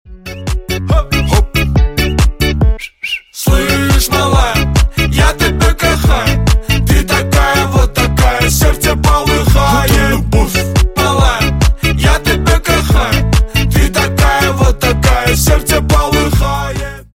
Весёлые Рингтоны
Поп Рингтоны
Скачать припев песни: